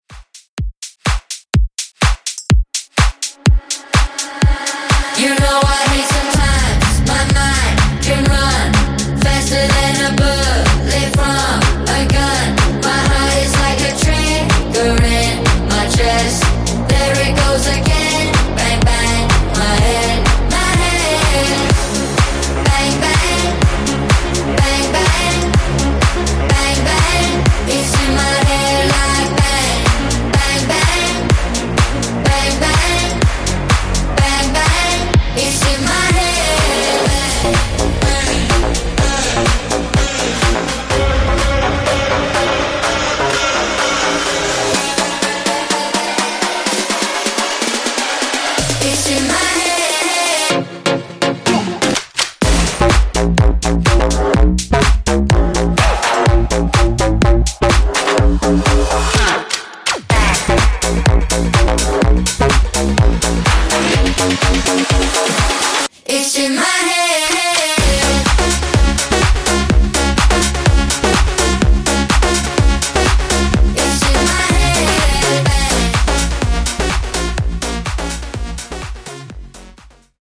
Club House Mix)Date Added